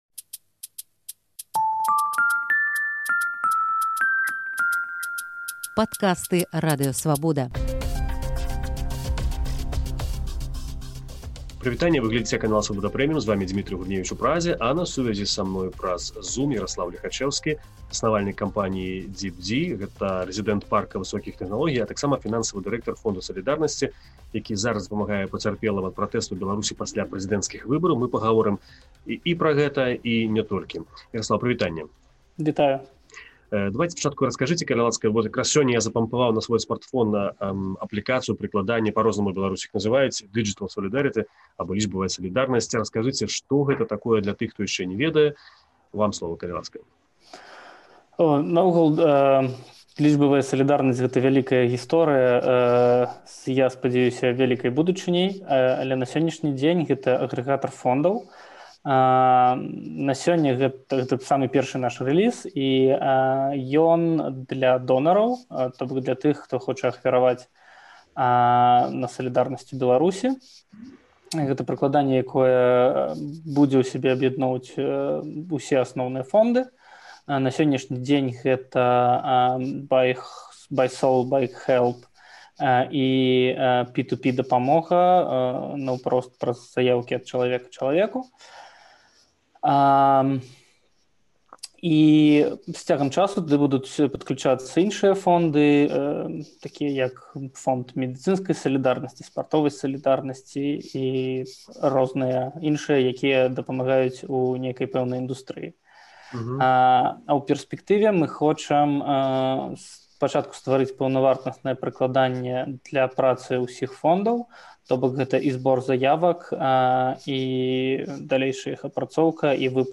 інтэрвію